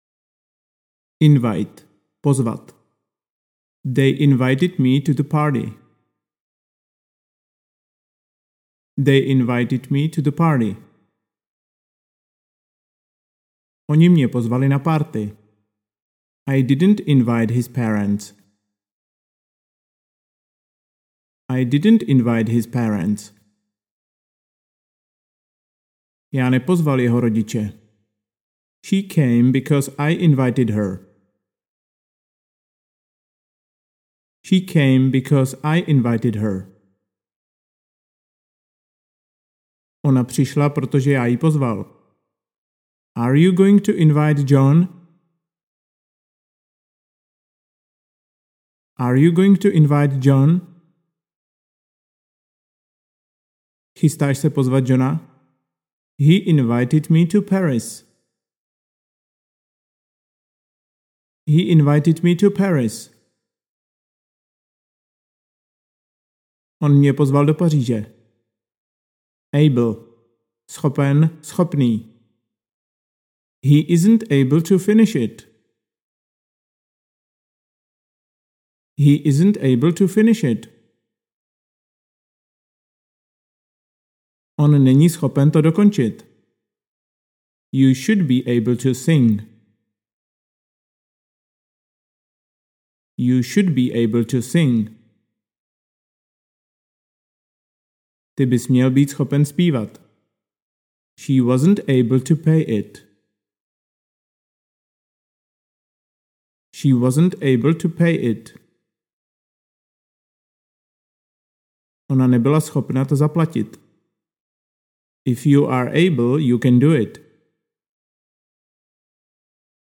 Anglická slovíčka - úroveň 4 pro pokročilé - audiokniha obsahuje jednoduché věty a slovíčka v angličtině pro snadné zapamatování a jejich využití.
Za každou anglickou větou je připraven český překlad. Nejsou to složité věty, kterým nebudete rozumět, ale jednoduché a praktické věty v přítomném, minulém a budoucím čase.